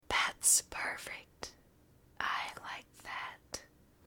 (Girl) - That's perfect. I like that.
Category: ASMR Mood: Relax Editor's Choice